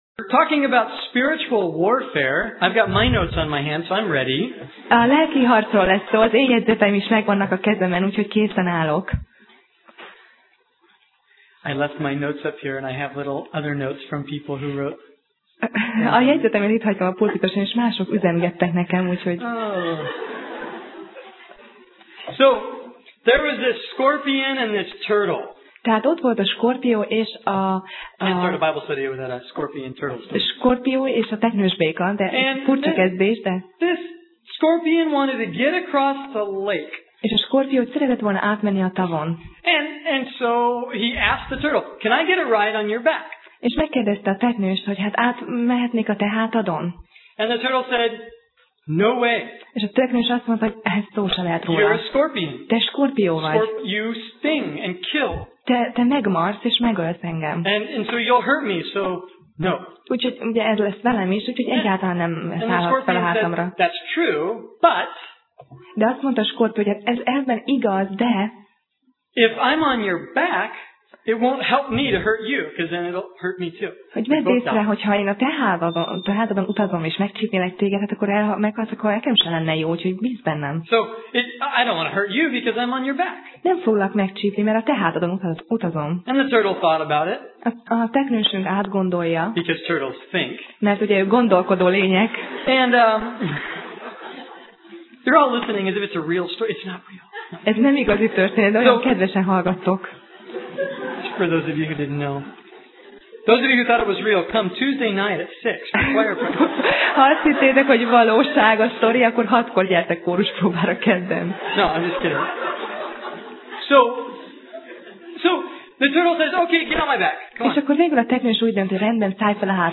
Sorozat: Tematikus tanítás Alkalom: Vasárnap Reggel